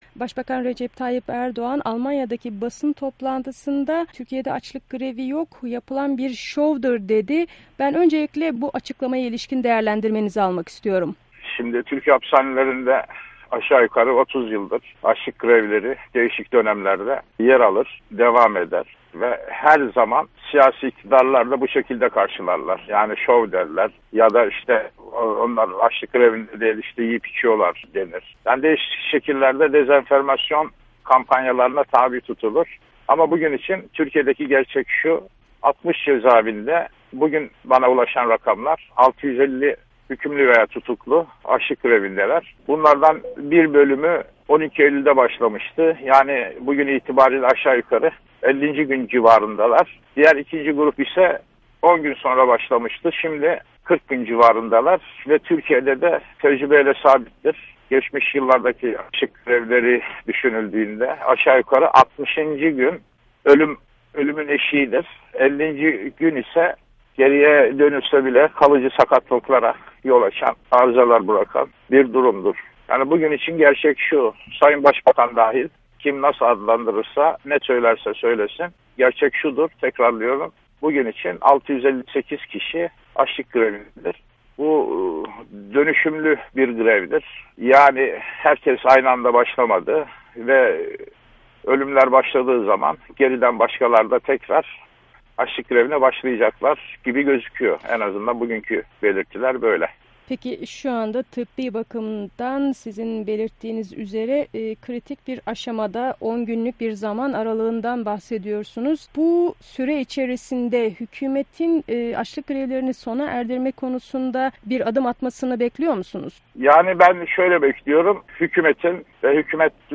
Röportajı